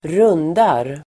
Uttal: [²r'un:dar]